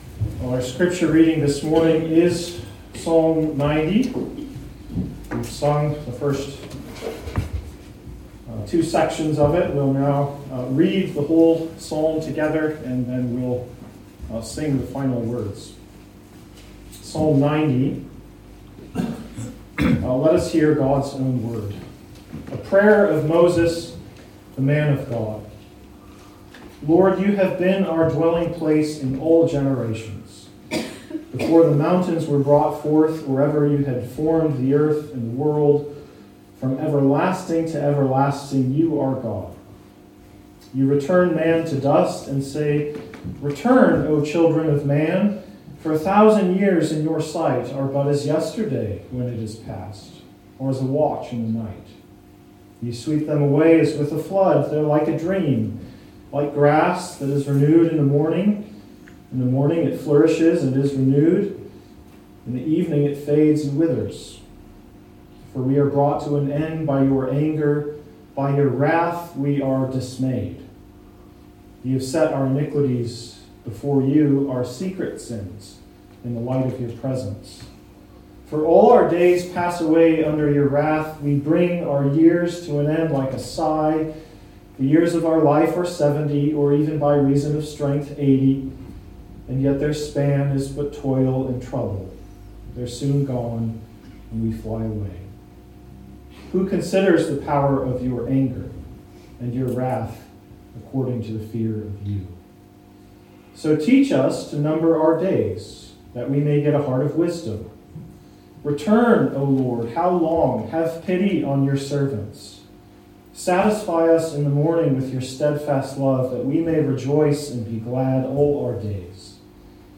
A link to the video recording of the 11:00am service, and an audio recording of the sermon.